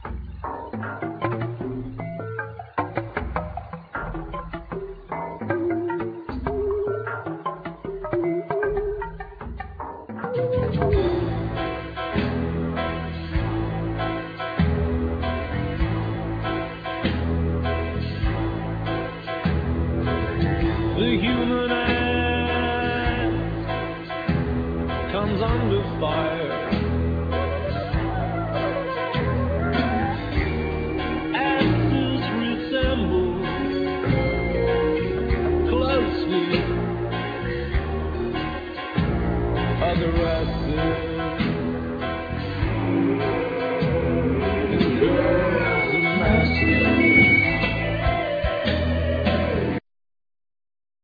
Vocal,Piano,Samples
Guitar,Banjo,Trombone
Drums
Double bass
Tenor saxophone
Accordion
Violin